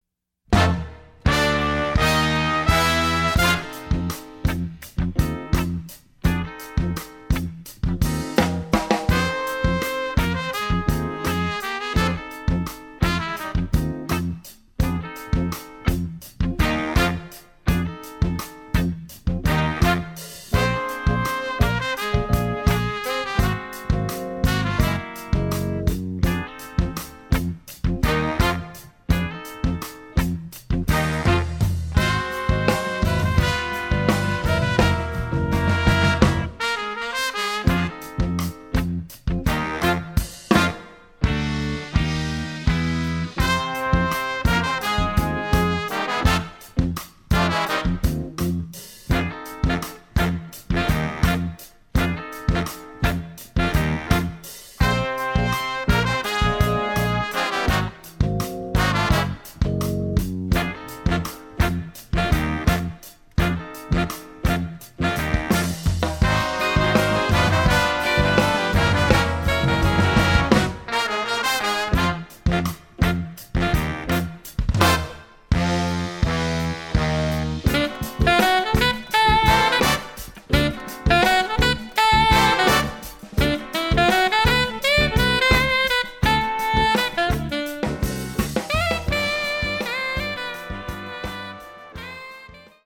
Jazz Band
Instrumentation is 5 saxes, 6 brass, 4 rhythm.